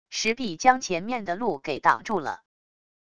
石壁将前面的路给挡住了wav音频生成系统WAV Audio Player